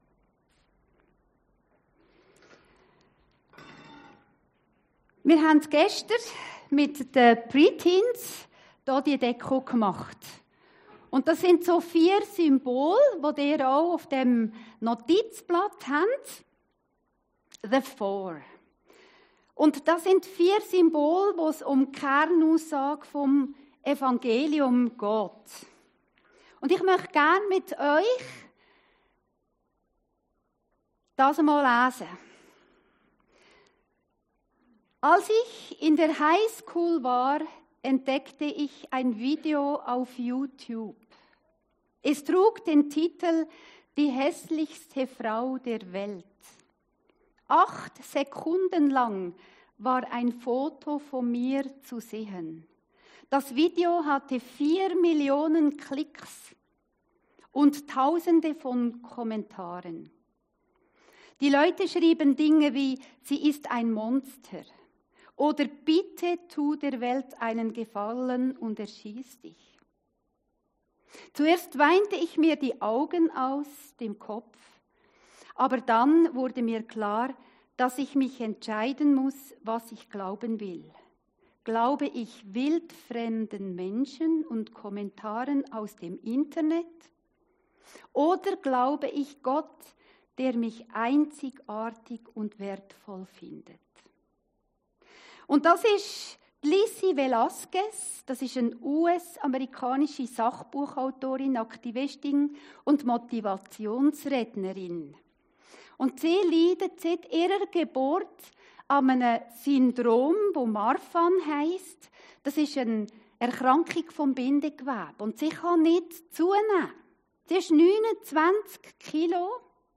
Predigten Heilsarmee Aargau Süd – The Four